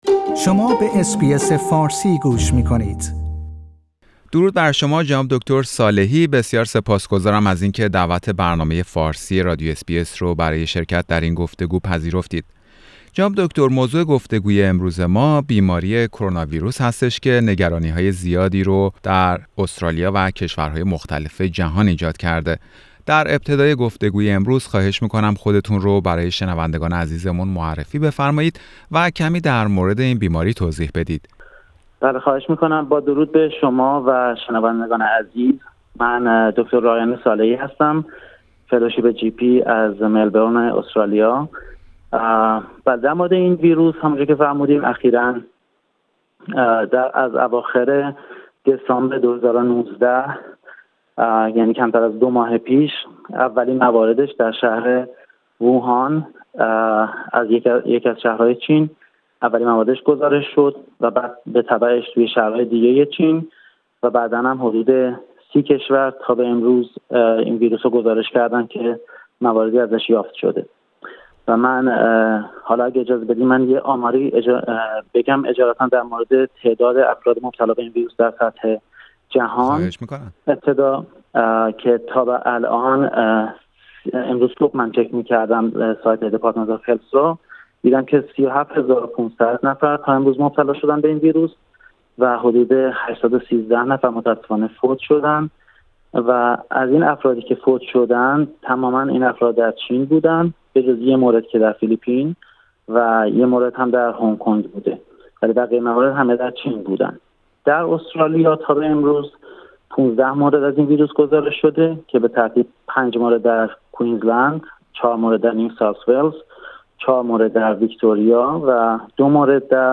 گفتگو با یک پزشک در مورد وضعیت کروناویروس در استرالیا و توصیه هایی برای پیشگیری از ابتلا به این بیماری